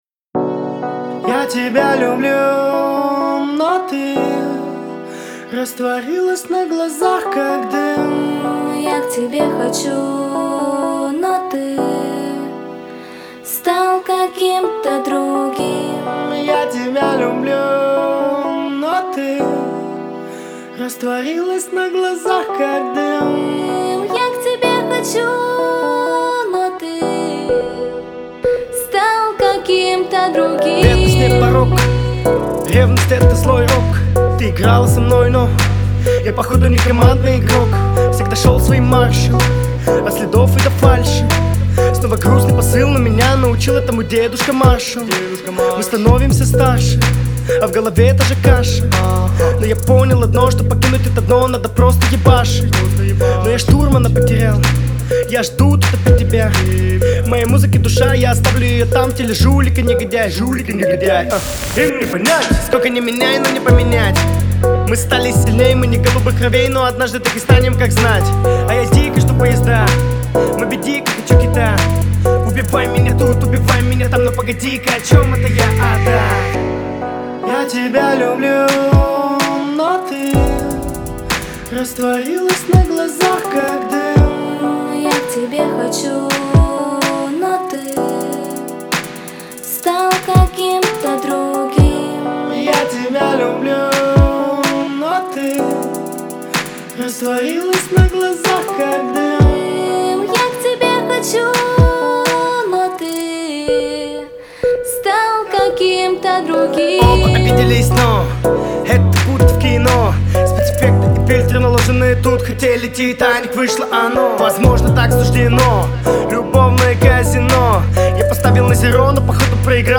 Rap, Pop